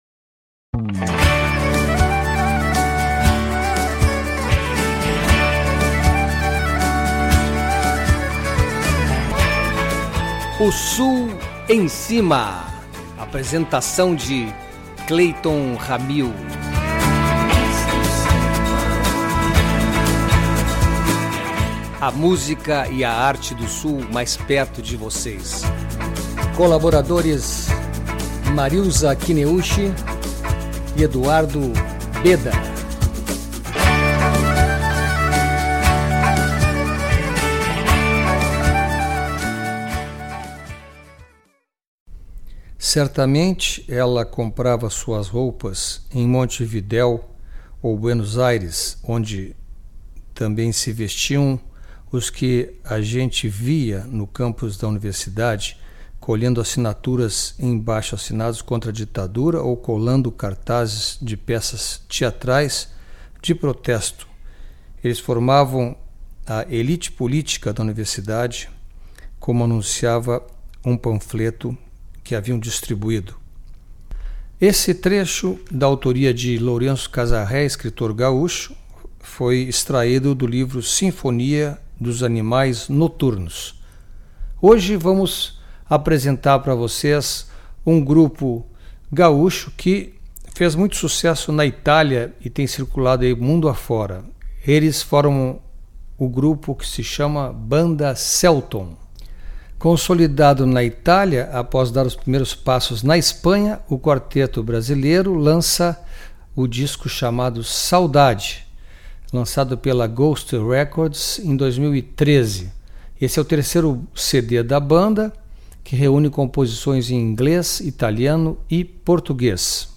voz, guitarra e ukelele
voz, guitarra e sintetizador
voz e baixo
voz, bateria e drum machine
numa estética mais roqueira